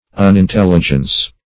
Unintelligence \Un`in*tel"li*gence\, n.